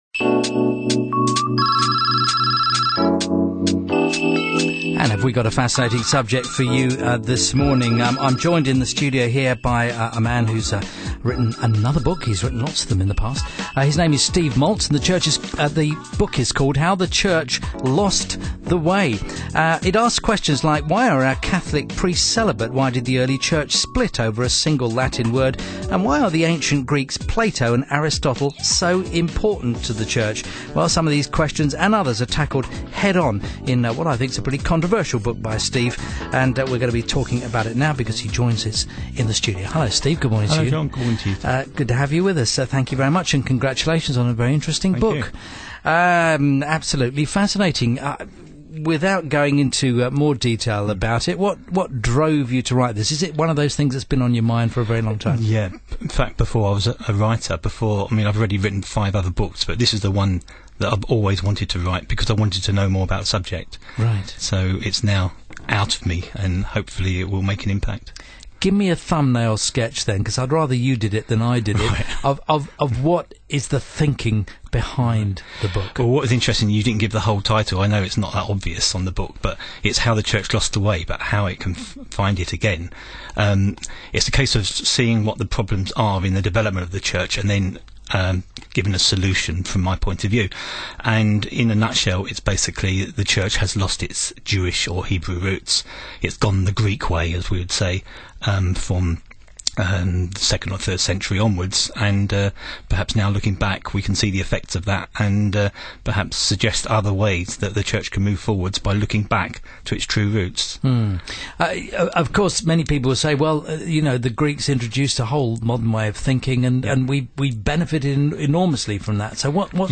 live breakfast phone-in show on Premier Radio. We have the full interview here.